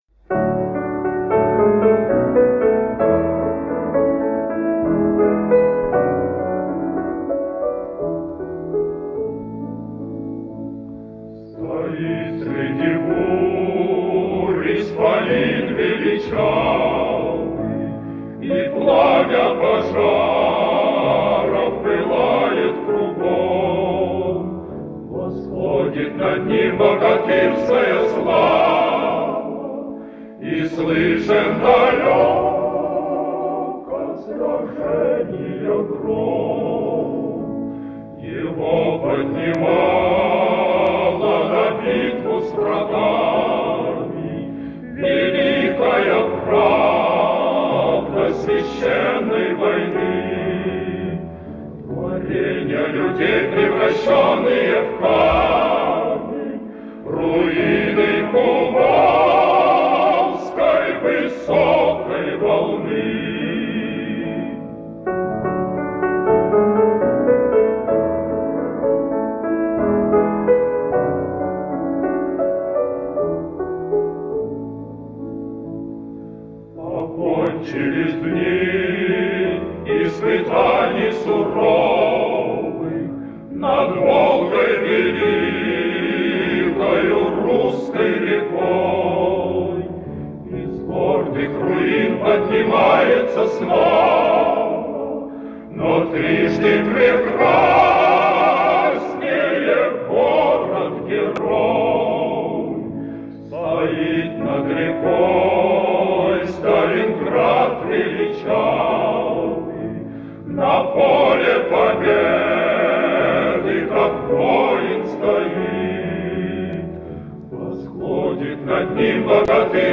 вокальный октет
фортепиано